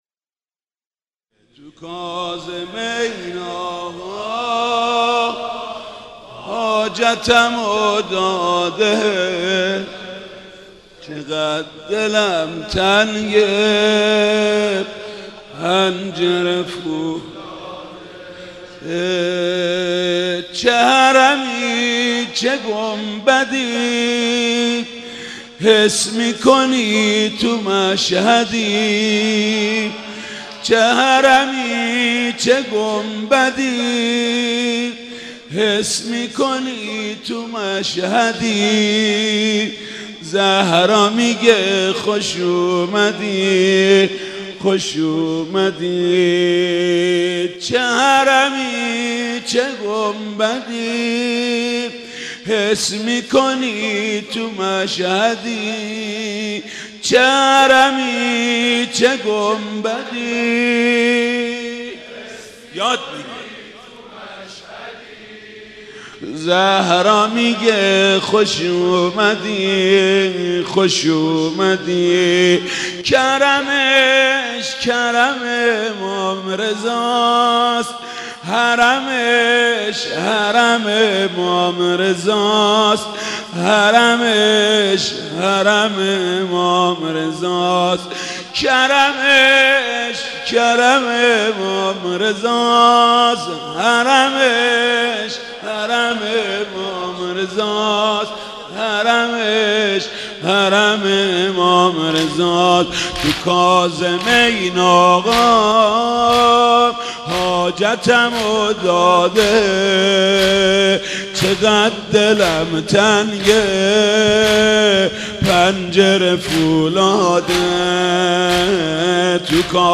مناسبت : شهادت امام موسی‌کاظم علیه‌السلام
قالب : زمینه